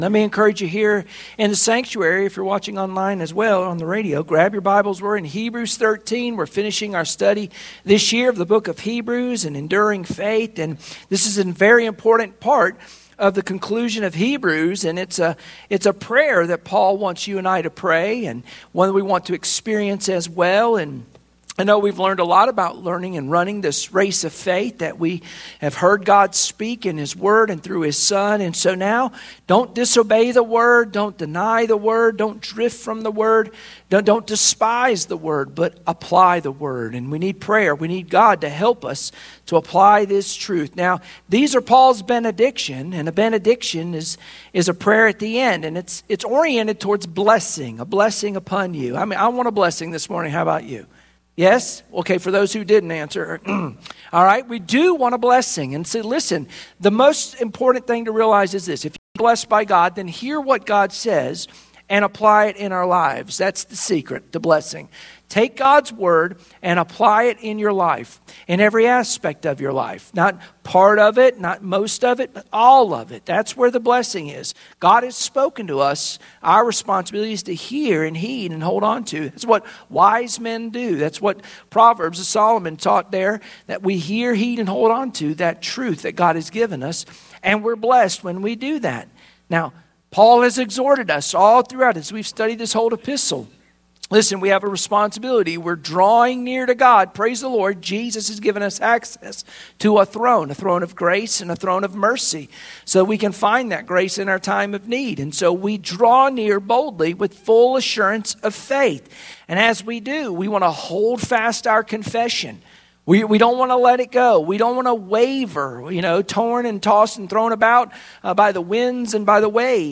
Sunday Morning Worship Passage: Hebrews 13:18-25 Service Type: Sunday Morning Worship Share this